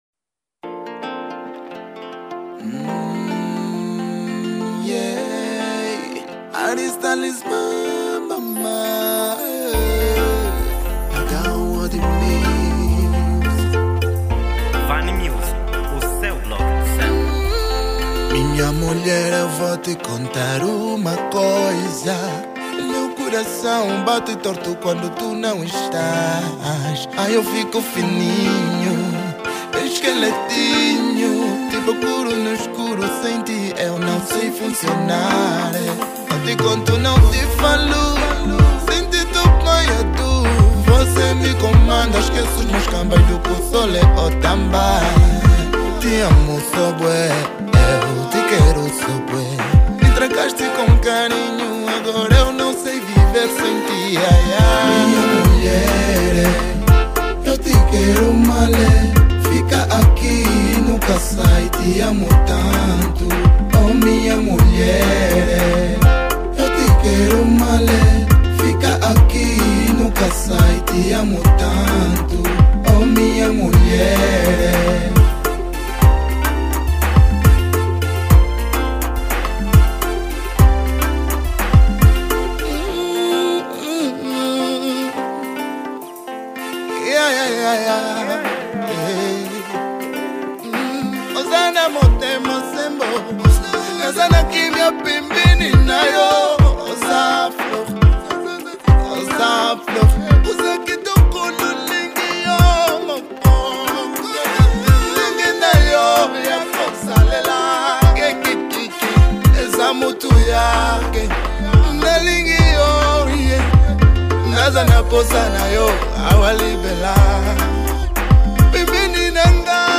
Estilo: Afro Naija